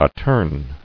[at·torn]